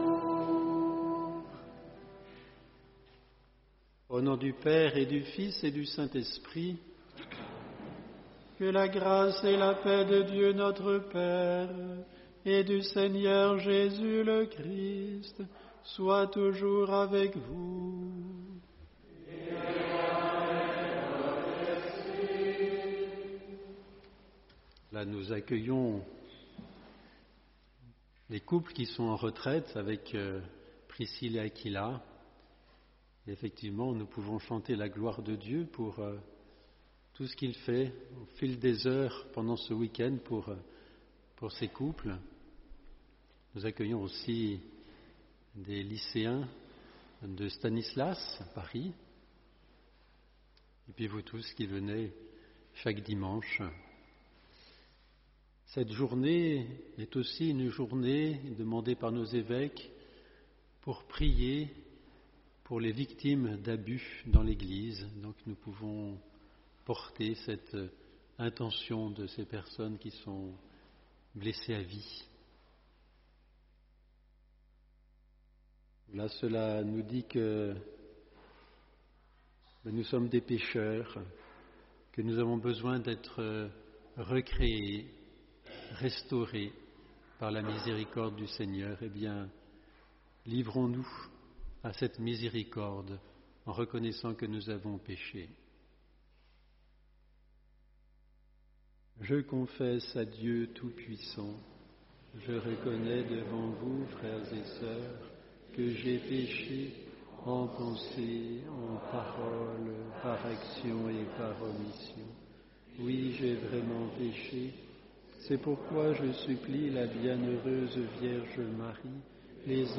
Homélie : Nous progressons sur notre chemin du Carême.